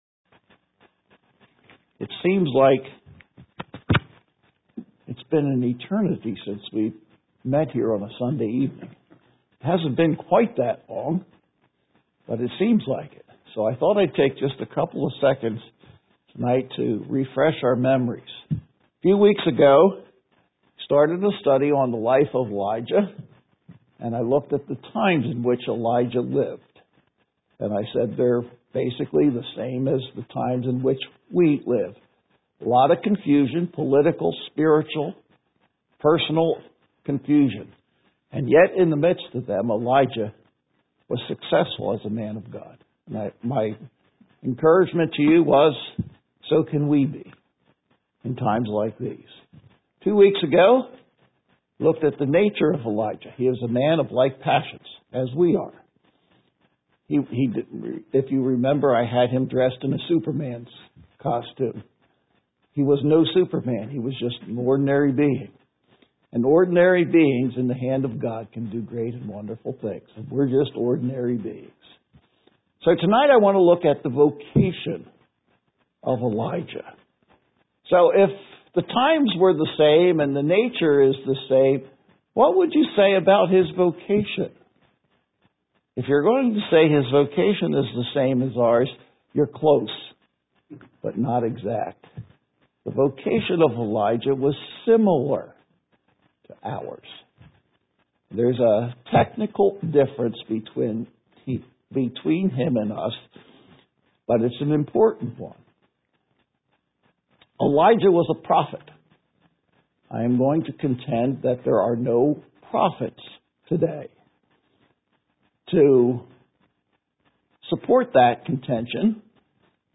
Worship Messages